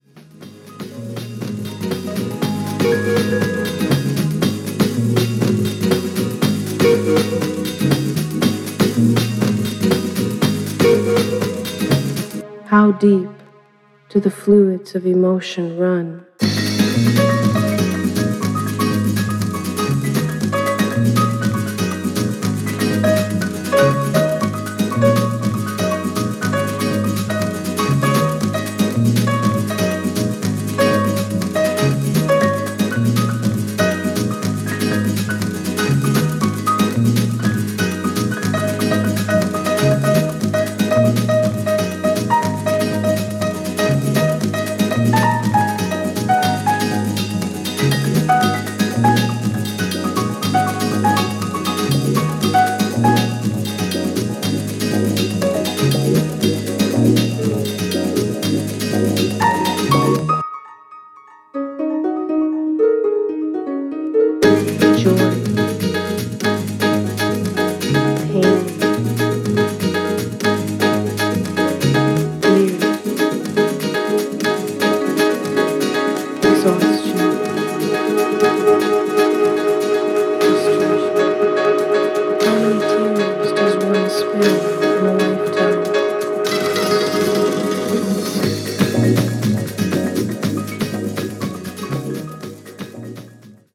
SAMPLE音源
Condition Media : EX-(音源録りしました)